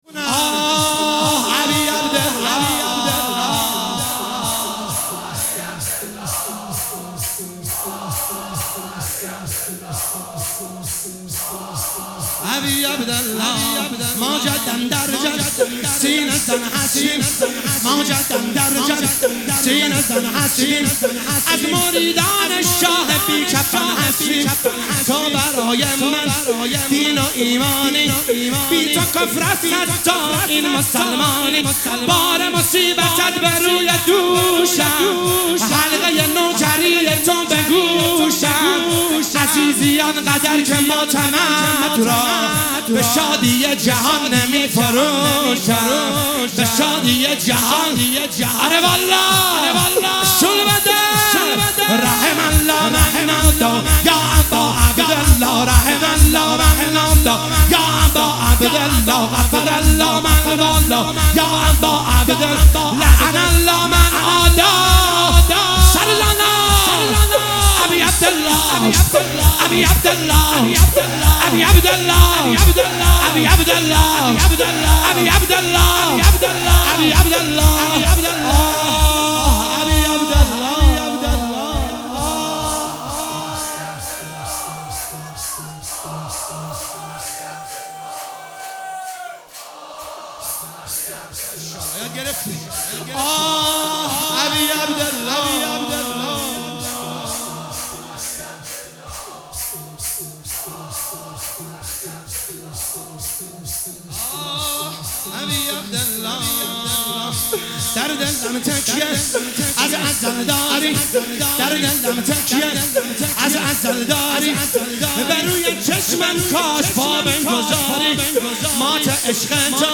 مداحی شور شب دوم محرم 1445